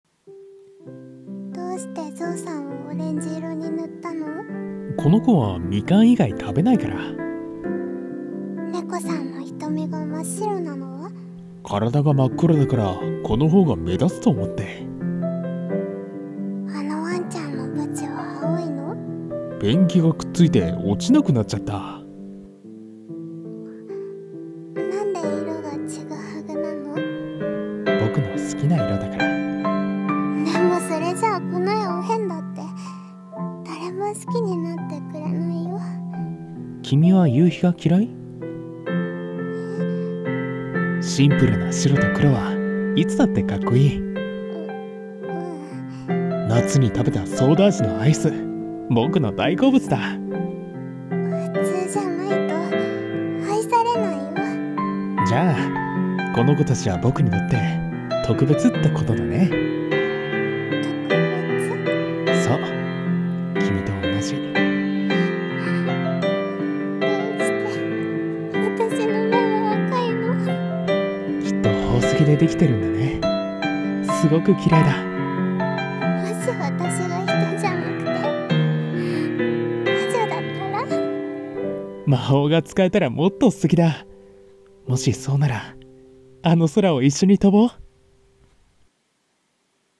二人声劇【とくべつないろ